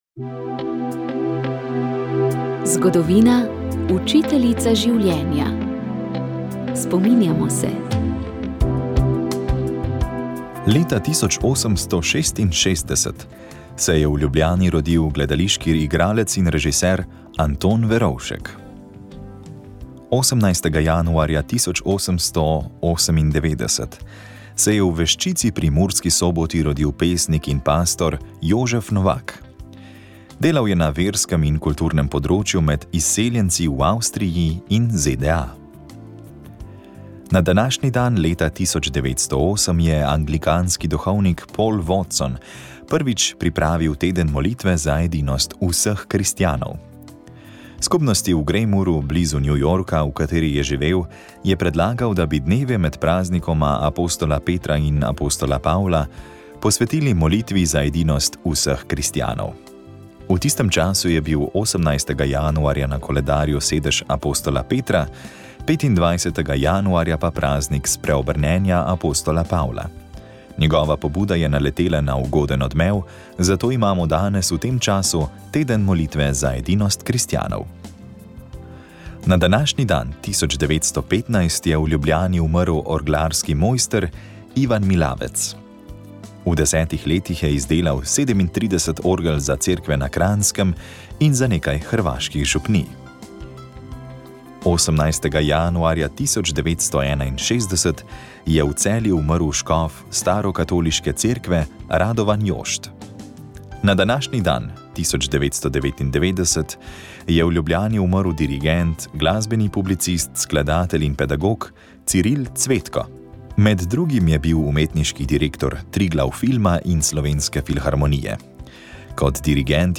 Prekmursko društvo Večerni zvon iz Toronta v Kanadi je v nedeljo opoldne pripravilo praznovanje Marijinega vnebovzetja - proščenje in pomurski dan.
V glasbenem delu sta sodelovala Peški oktet in ansambel Raubarji. Glasbeni gostje iz Slovenije so že v soboto zvečer peli pri sveti maši v župniji Brezmadežne na Browns Line v Torontu.